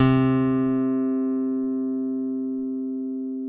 ALEM PICK C3.wav